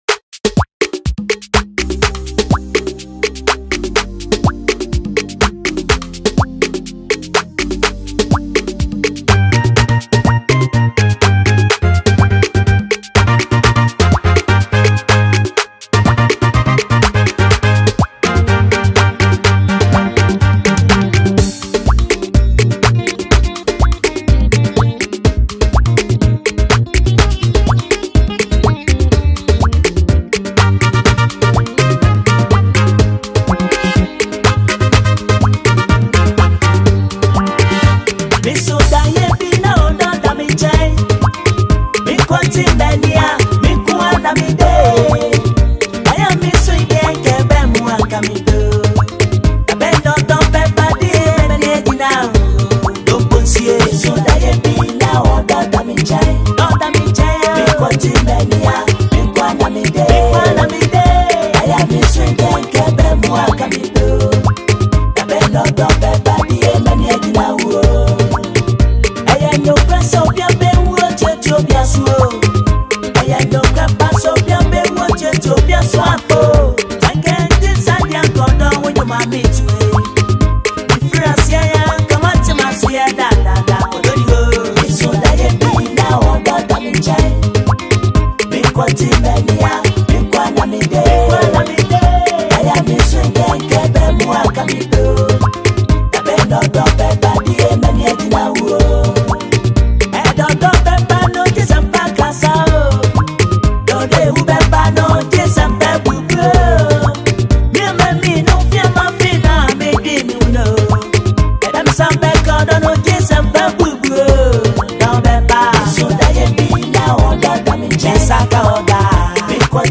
and classic instrumentation
” is a heartfelt highlife song that tells a story of hope
soothing vocals